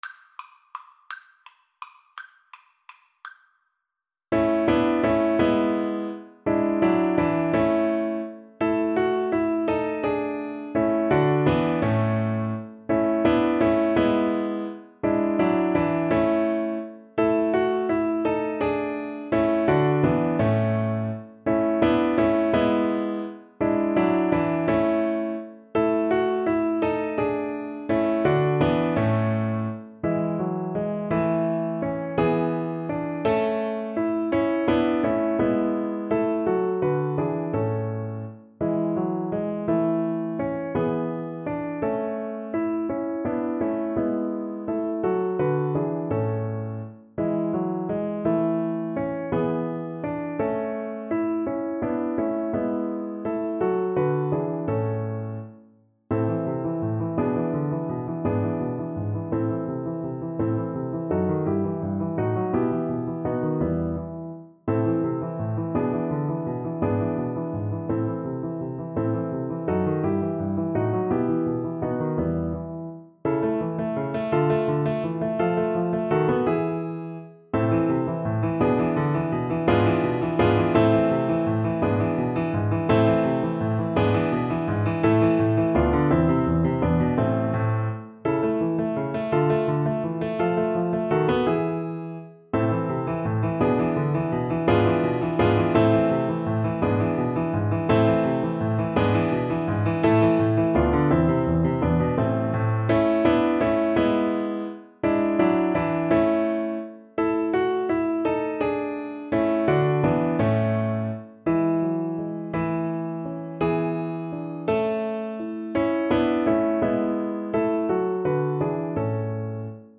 3/8 (View more 3/8 Music)
Lustig (Happy) .=56
Classical (View more Classical Cello Music)